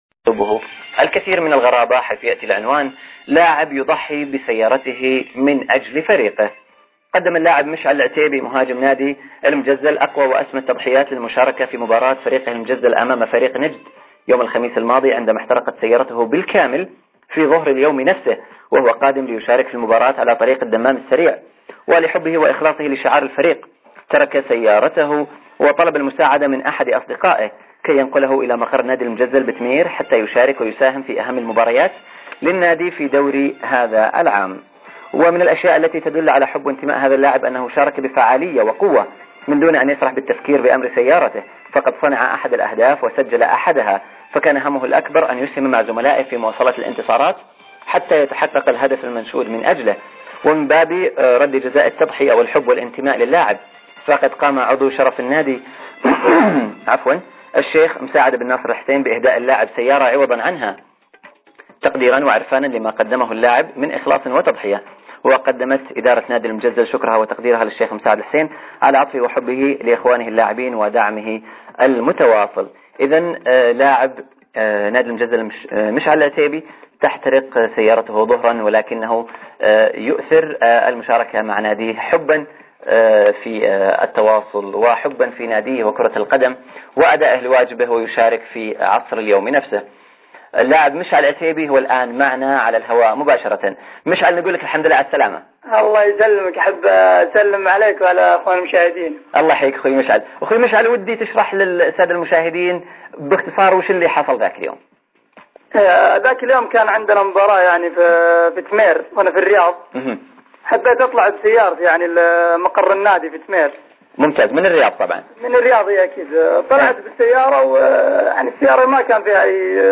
التسجيل الصوتي ويشمل " عرض الخبر + الإتصال باللاعب "